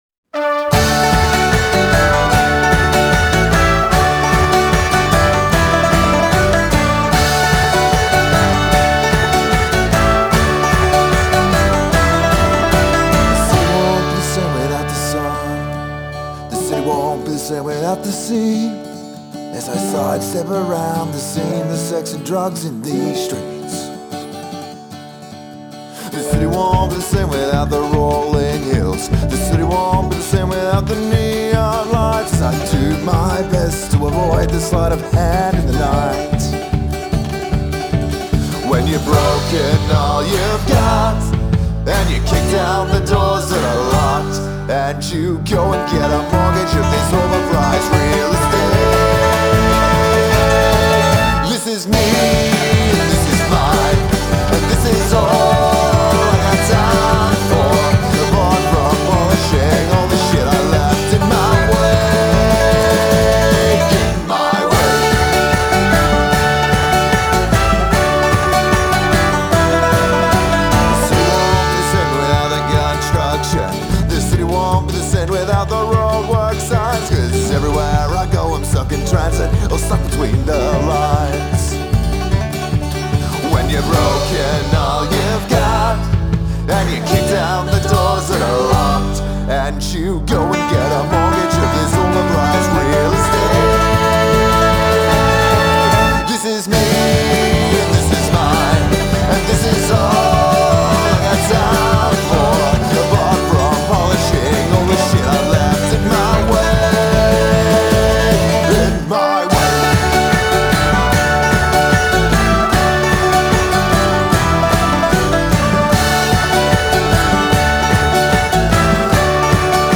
Genre: Folk, Roots, Alternative